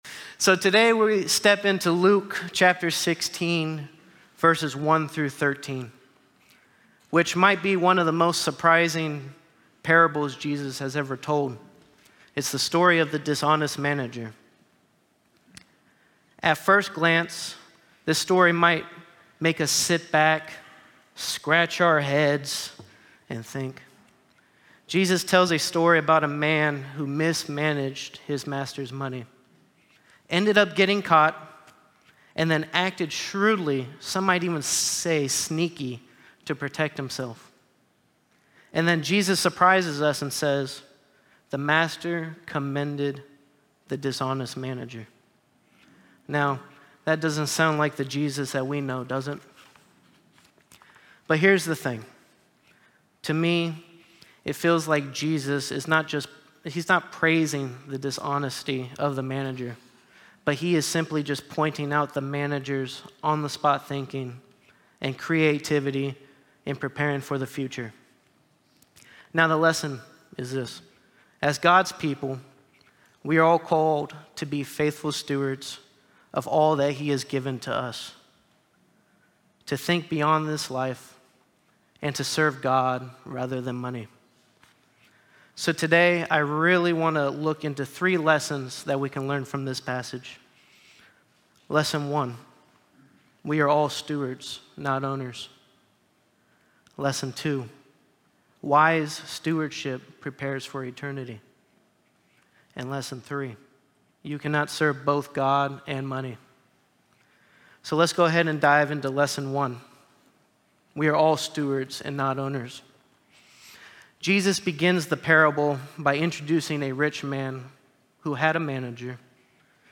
Sermon from Sunday, September 21, 2025